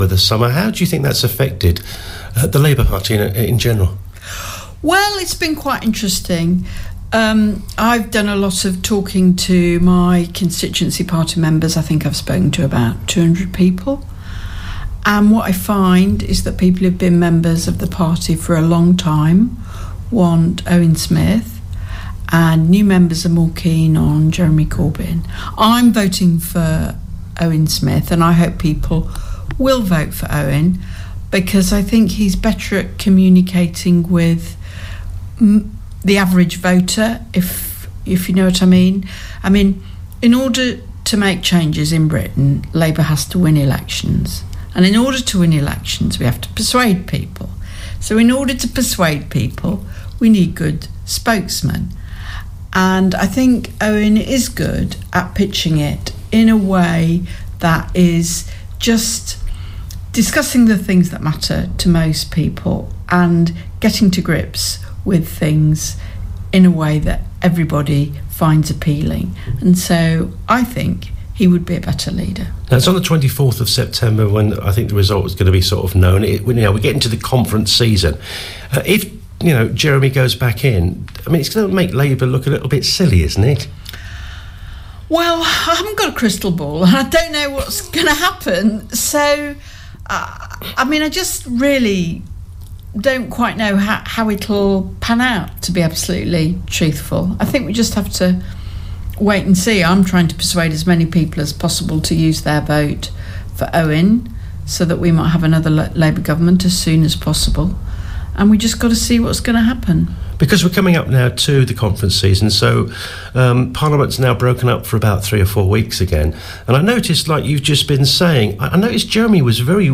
Bishop Auckland MP Helen Goodman spoke to us recently